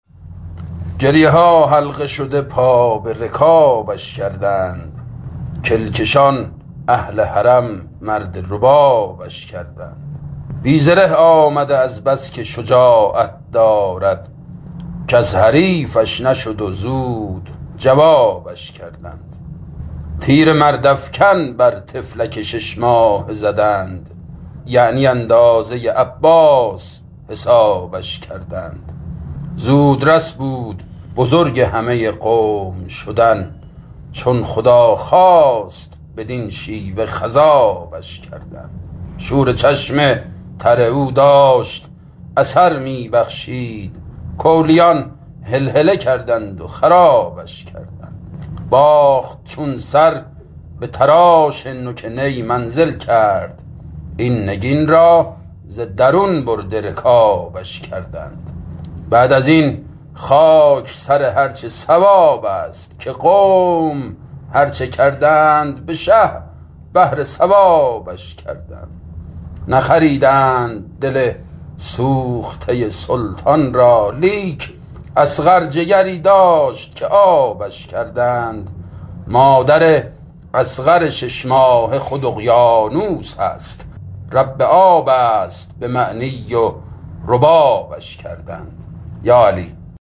صوت شعر خوانی شاعران آیینی در ایام محرم